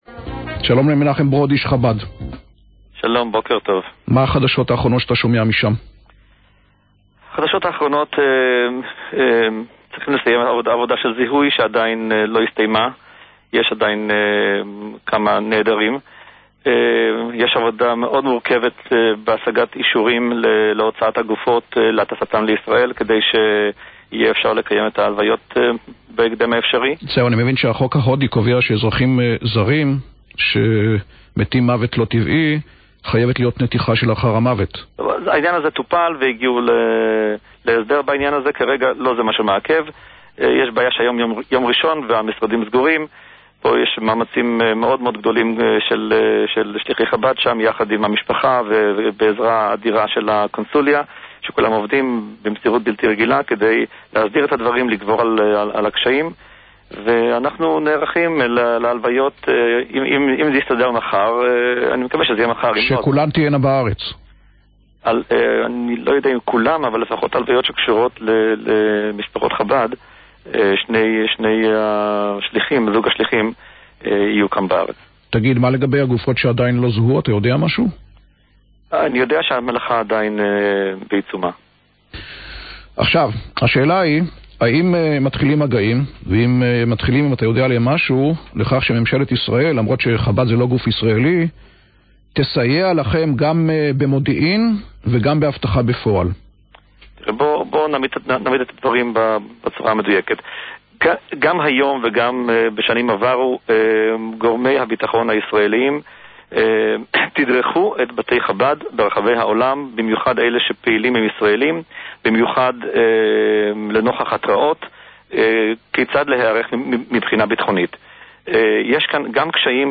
מתראיין אצל רזי ברקאי
(בתוכנית רואיין גם השר דיכטר בנושא האבטחה)